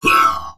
文件 文件历史 文件用途 全域文件用途 Enjo_atk_03_3.ogg （Ogg Vorbis声音文件，长度0.6秒，154 kbps，文件大小：11 KB） 源地址:地下城与勇士游戏语音 文件历史 点击某个日期/时间查看对应时刻的文件。